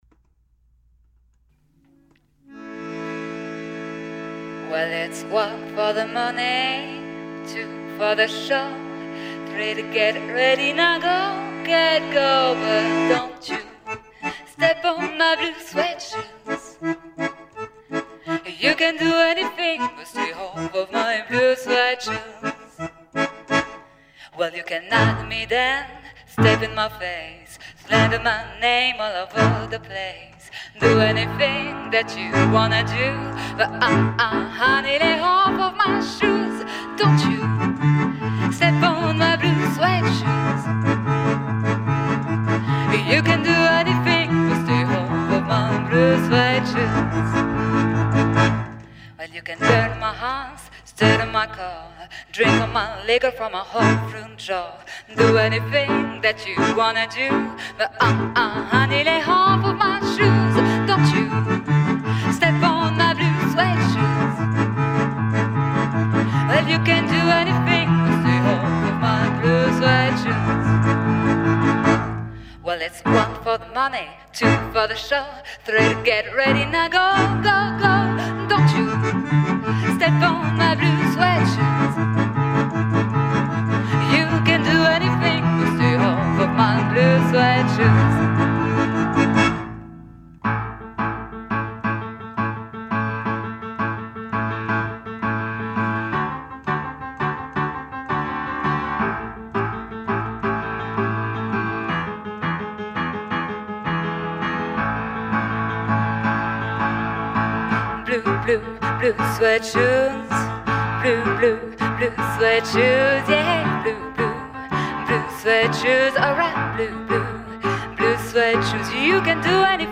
Жанр: instrumental, chanson à texte.
Фортепиано, гитара, вокал, аккордеон, скрипка.